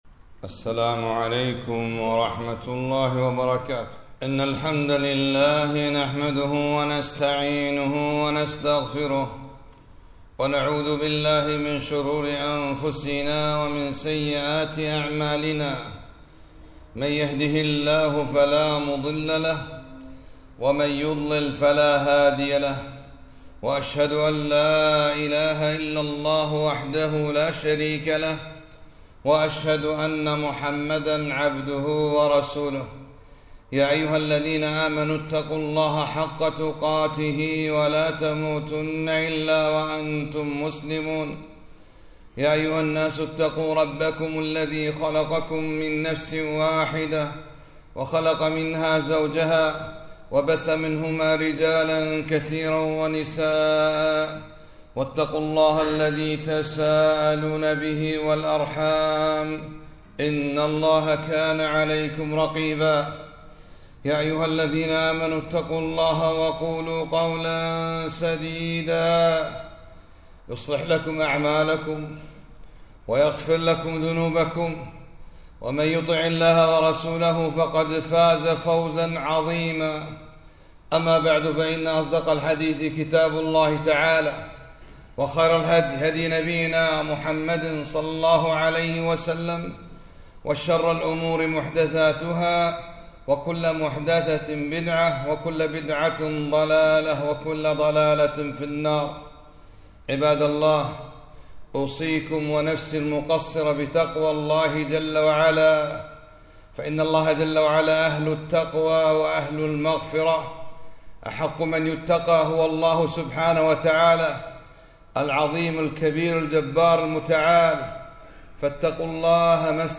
خطبة - صلاة الاستخارة